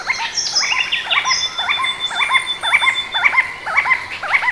Resplendent Quetzal
A recording of the Resplendent Quetzal's flight call ('perwicka') [.wav]
(All of these samples are 8-bit, sorry.)
quetzal.wav